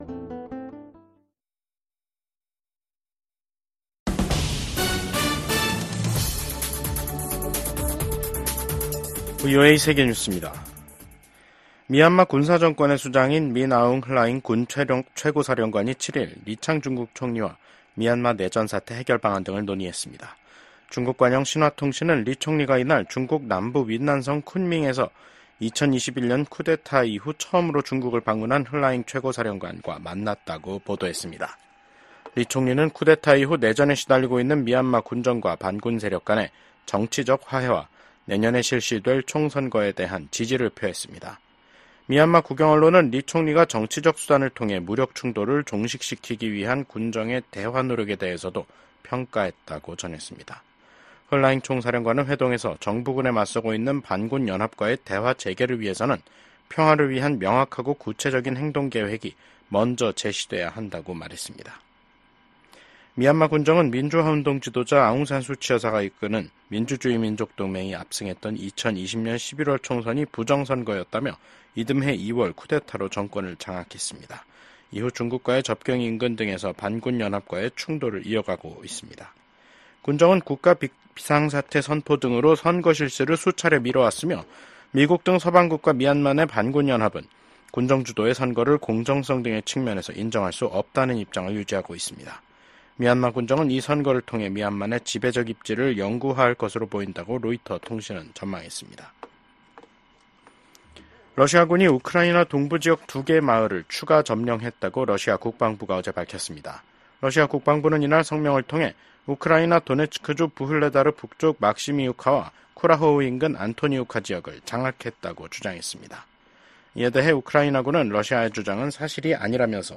VOA 한국어 간판 뉴스 프로그램 '뉴스 투데이', 2024년 11월 7일 2부 방송입니다. 제47대 대통령 선거에서 도널드 트럼프 전 대통령에게 패한 카멀라 해리스 부통령이 패배를 공개적으로 인정했습니다. 윤석열 한국 대통령은 도널드 트럼프 미국 대통령 당선인과 전화통화를 하고 양국의 긴밀한 협력관계 유지에 공감했습니다. 도널드 트럼프 대통령 당선인이 2기 행정부에서 첫 임기 때와 다른 대북 접근법을 보일 수도 있을 것으로 전문가들은 전망했습니다.